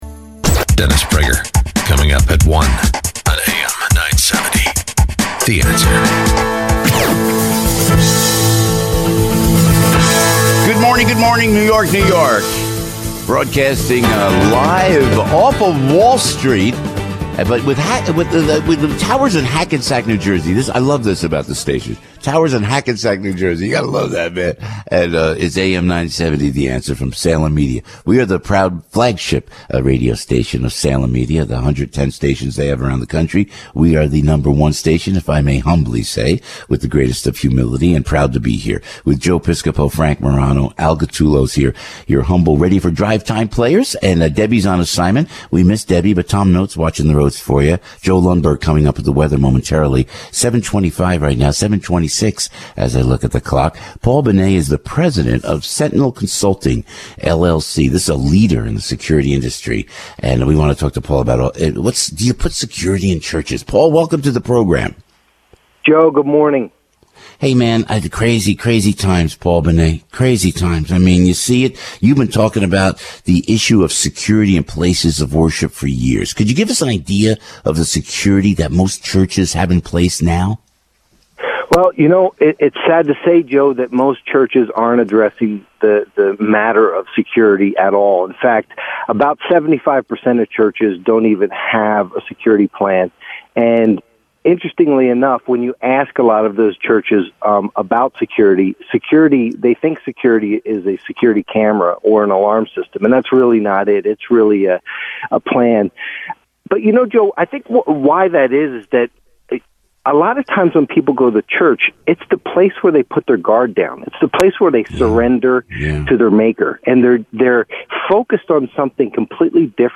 I was invited to share some thoughts on the topic of Church Security on the Joe Piscopo show on AM970 in New York City.